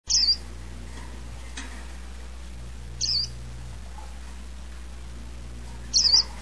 さえずりのコーナー（メジロ編）
ピイピイ 31KB ピイ！を三回鳴いただけという話もありますが^^;
saezuri-tii.mp3